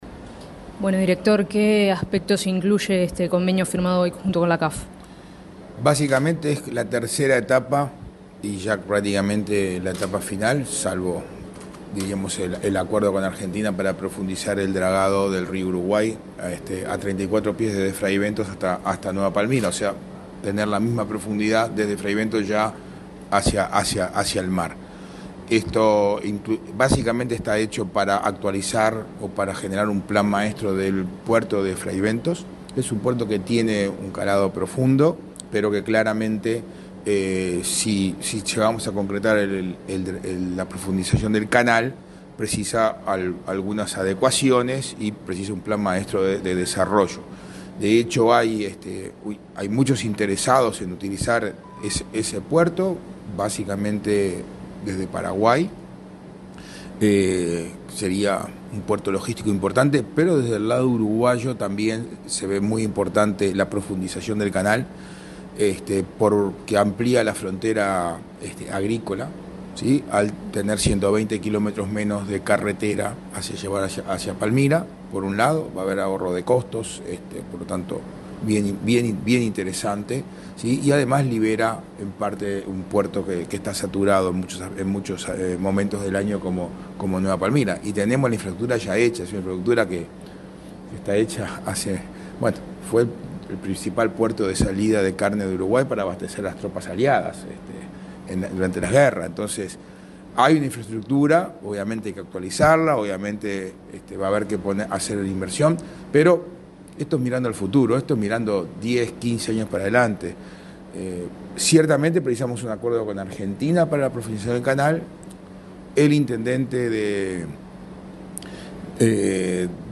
Declaraciones del director de OPP, Isaac Alfie